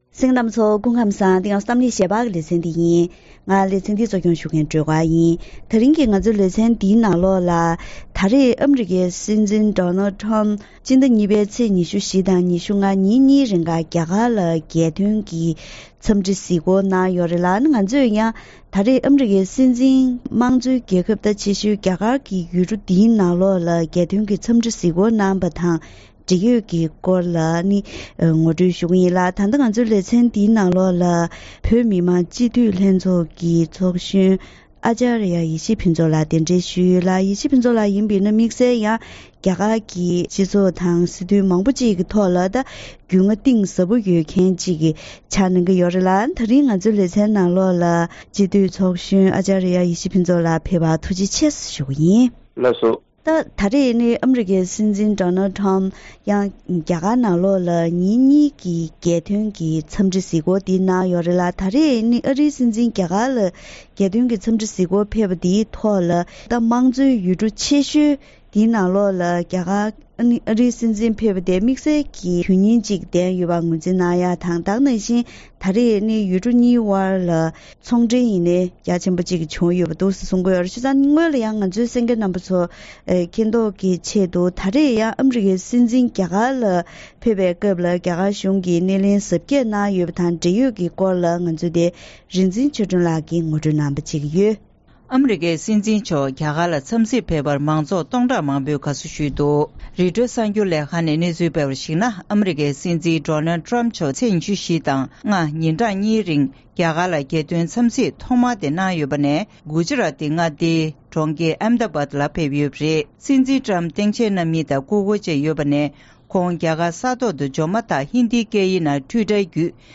དེ་རིང་གི་གཏམ་གླེང་ཞལ་པར་ལེ་ཚན་ནང་ཨ་རིའི་སྲིད་འཛིན་ཊྲ་རམ་པ་(Trump)རྒྱ་གར་ལ་ཉིན་གཉིས་ཀྱི་རྒྱལ་དོན་འཚམས་འདྲི་གཟིགས་སྐོར་གནང་ཡོད་པ་དང་། རྒྱ་གར་གཞུང་གི་སྣེ་ལེན་གཟབ་རྒྱས་ཞུས་ཡོད་ལ། ཡུལ་གྲུ་གཉིས་ཀྱི་འབྲེལ་བ་གཏིང་ཟབ་ཏུ་ཕྱིན་པ་དང་དུས་མཚུངས་ཨ་སྒོར་ཐེར་འབུམ་གསུམ་གྱི་ཚོང་འབྲེལ་བྱུང་ཡོད་པ་སོགས་ཀྱི་སྐོར་ལ་འབྲེལ་ཡོད་དང་ལྷན་དུ་བཀའ་མོལ་ཞུས་པ་ཞིག་གསན་རོགས་གནང་།